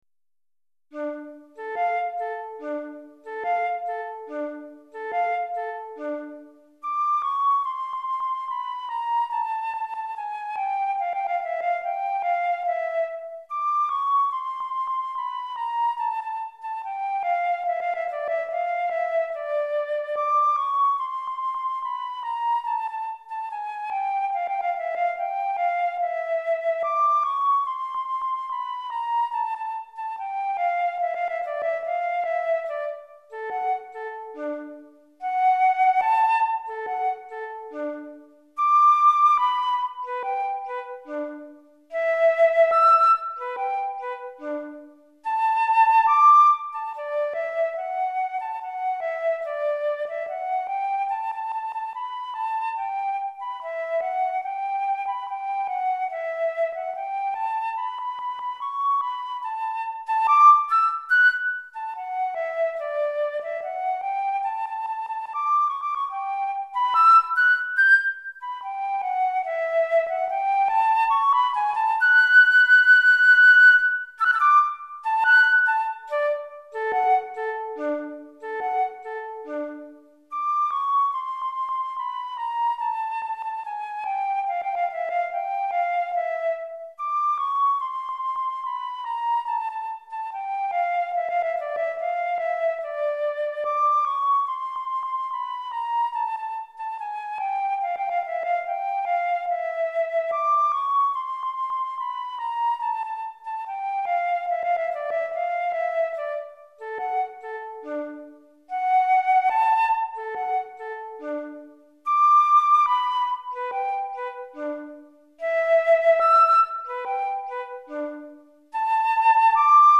Flûte Traversière Solo